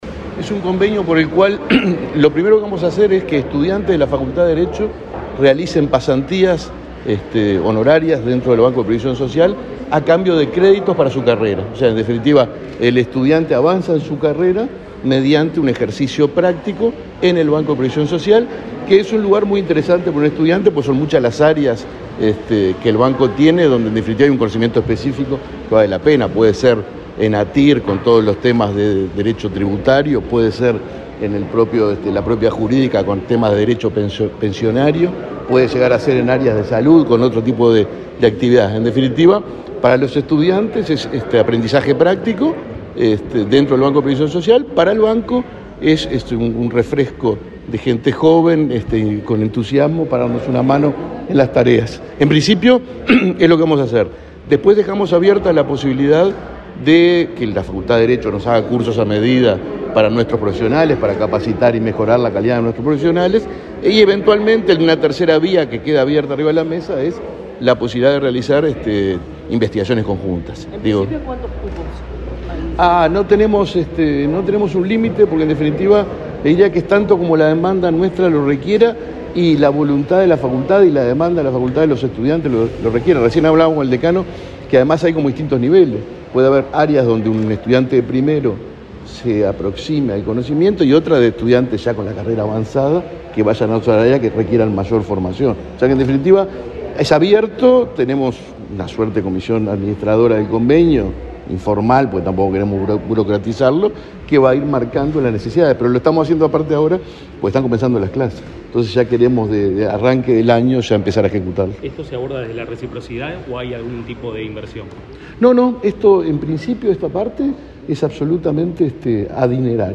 Declaraciones del presidente del BPS, Alfredo Cabrera, a la prensa
Luego, Cabrera dialogó con la prensa.